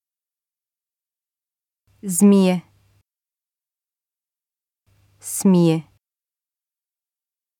U krijgt per keer 2 woorden achter elkaar te horen.